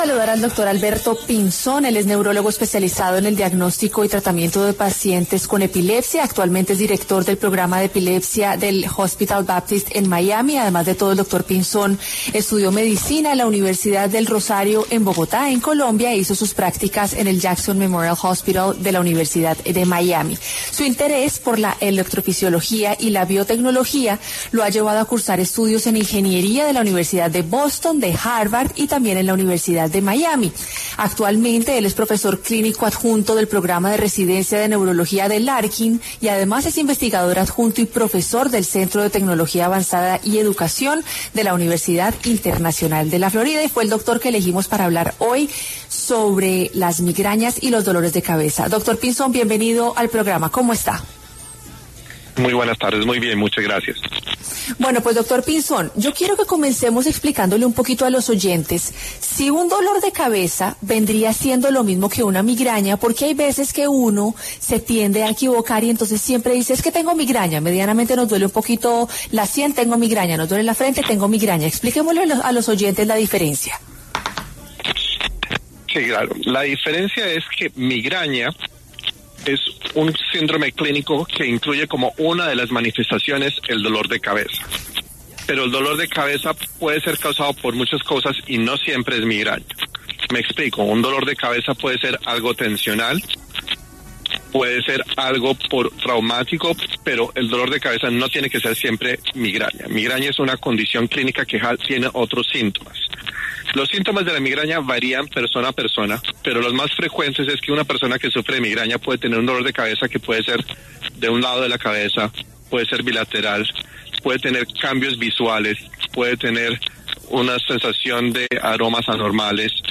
Entrevista_Completa_Migraña_92826_cut.mp3